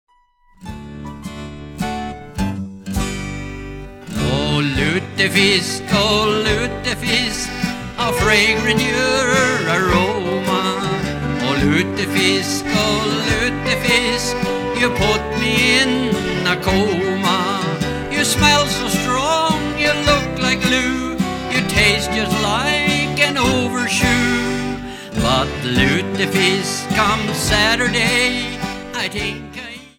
Current Location: Genre Folk Music